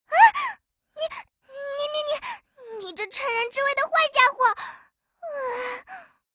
贡献 ） 协议：Copyright，人物： 碧蓝航线:肇和语音 您不可以覆盖此文件。